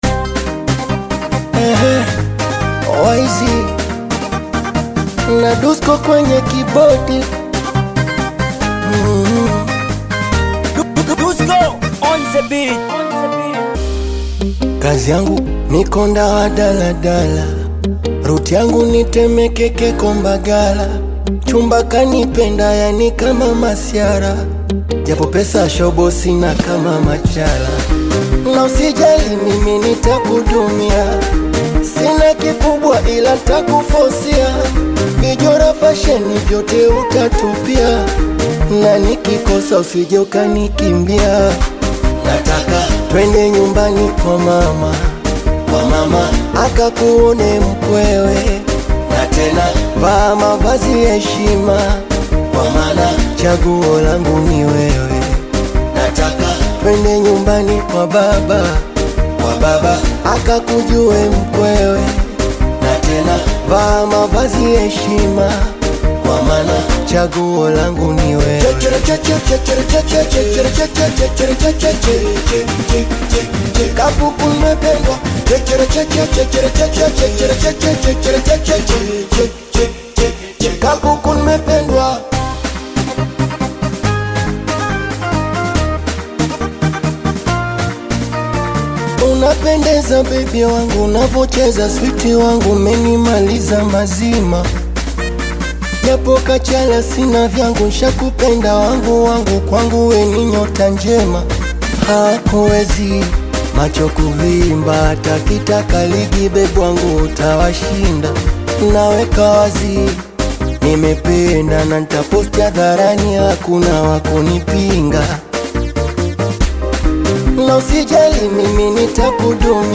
high-energy singeli anthem
rapid-tempo beats and catchy chants
Genre: Bongo Flava